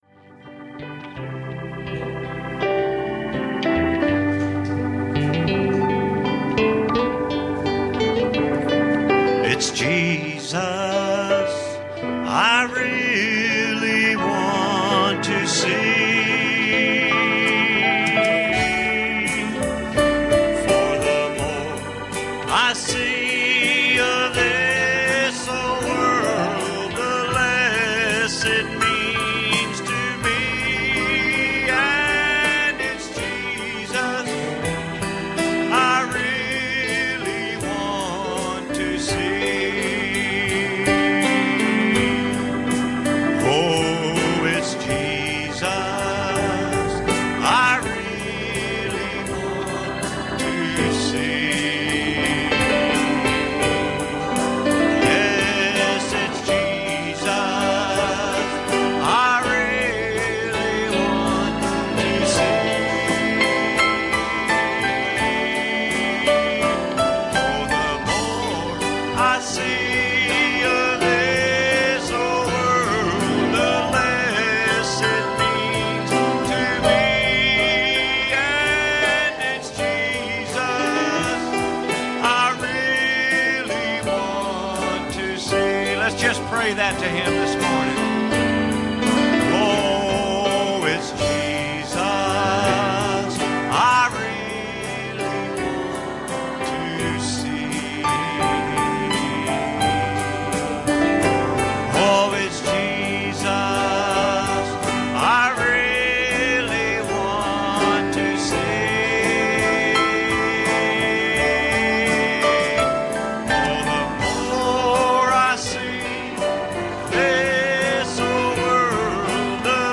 Living Like A Dead Man – Church Of The Open Door
Service Type: Sunday Morning